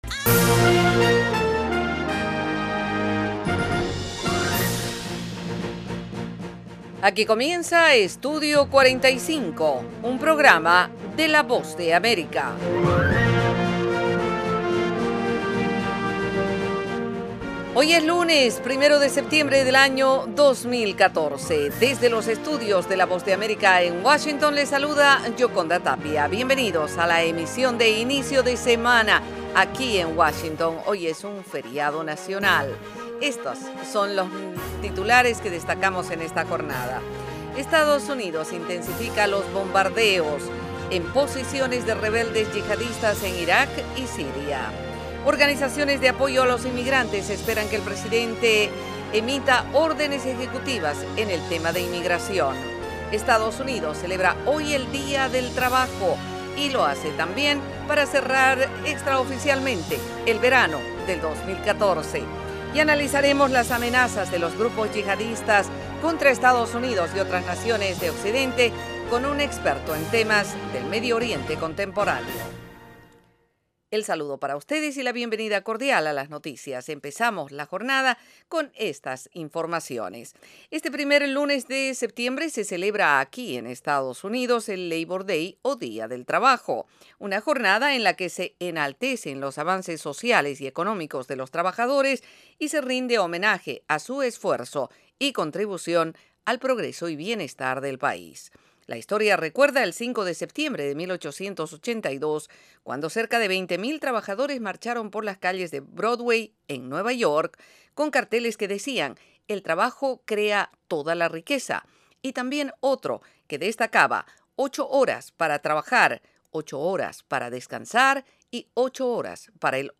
El programa ofrece -en 30 minutos- la actualidad noticiosa de Estados Unidos con el acontecer más relevante en América Latina y el resto del mundo.